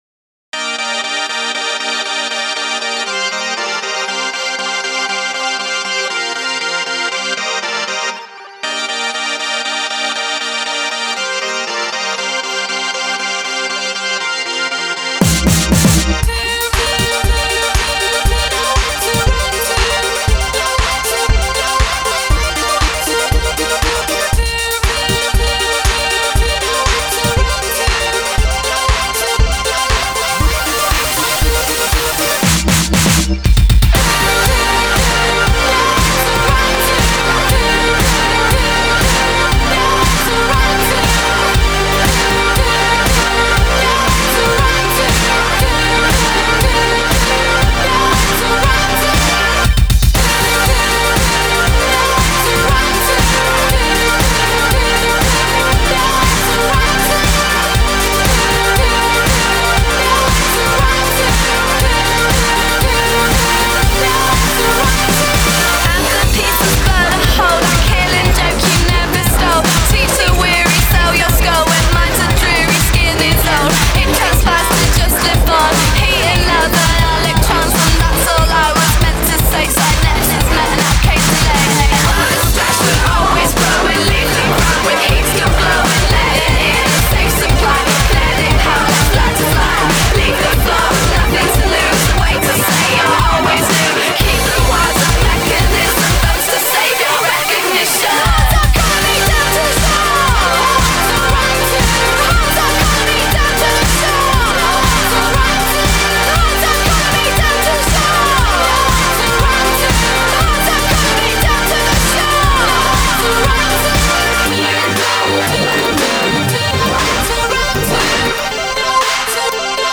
I actually like this kind of electro
same NES chip in the Synths i see.